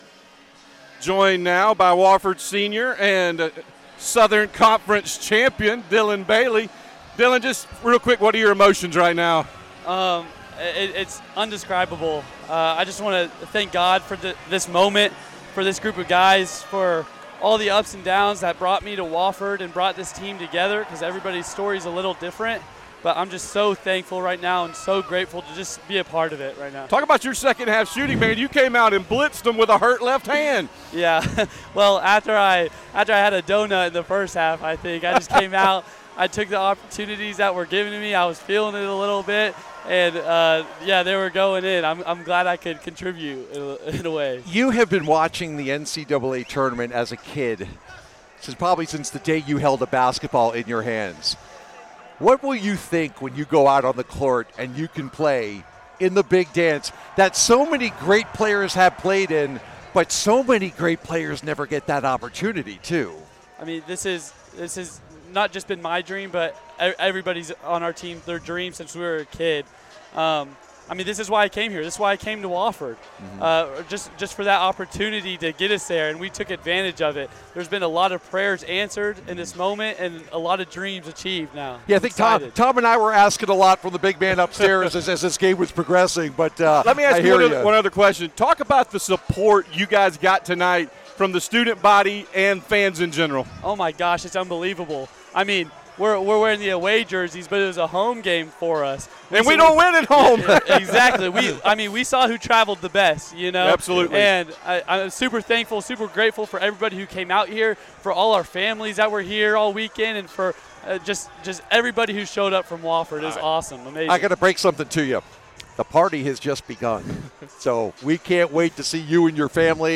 March 11, 2025 Download Listen Now All Categories Postgame Audio All Sports Men's Basketball Women's Basketball Loading More Podcasts...